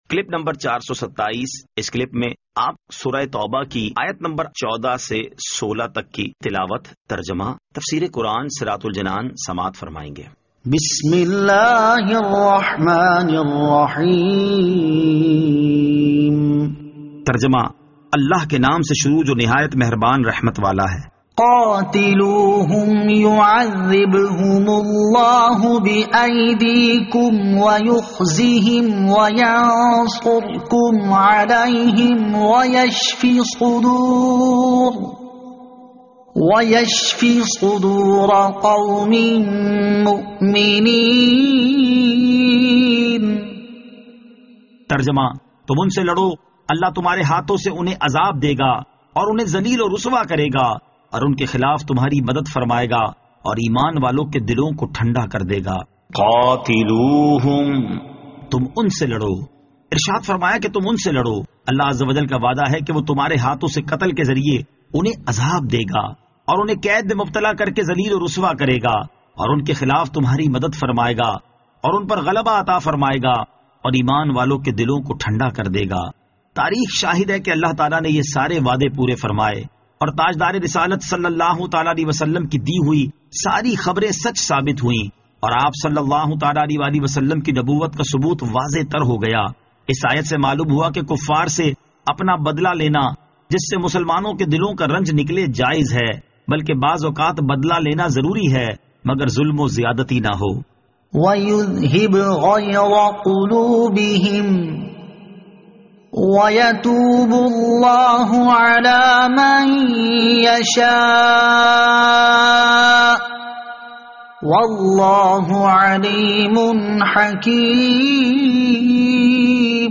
Surah At-Tawbah Ayat 14 To 16 Tilawat , Tarjama , Tafseer